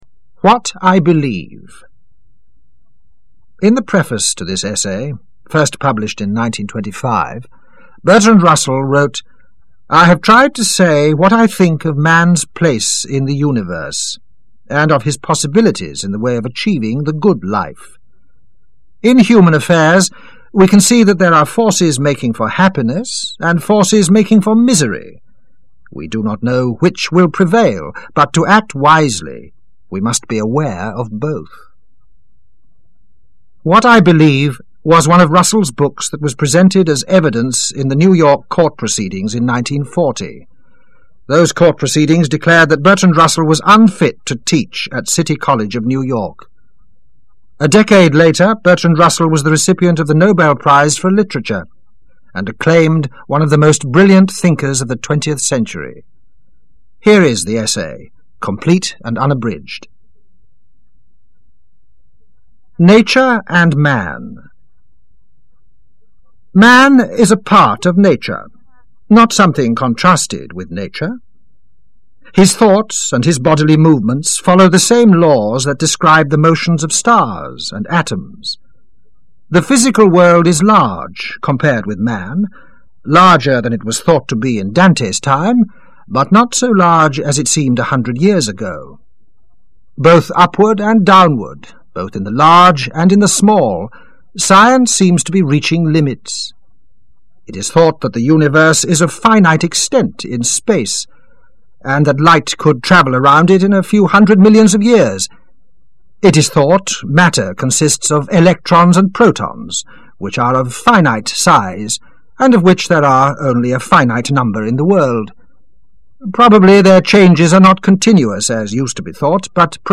(It’s not him doing the reading–his voice, which I may share with you later–was not nearly as appealing as this reader’s.)